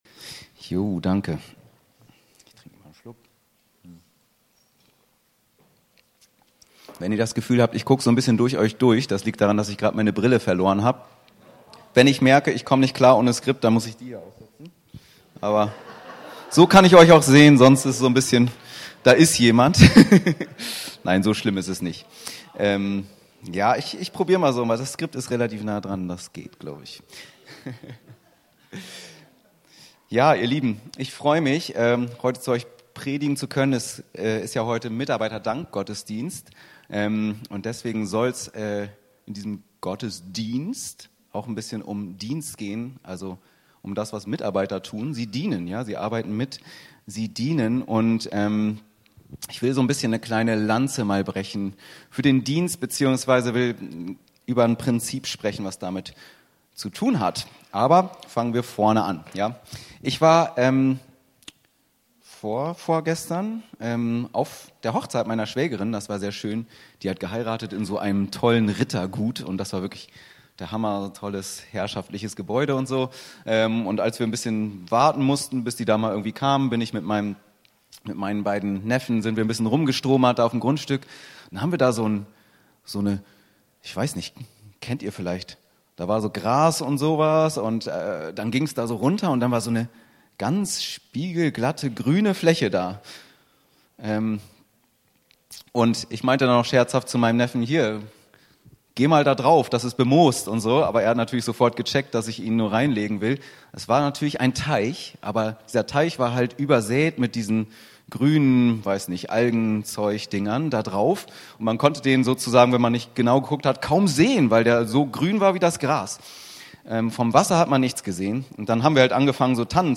In dieser Predigt wollen wir schauen, wie wir da hinkommen.